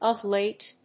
アクセントof láte